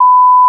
1khz.wav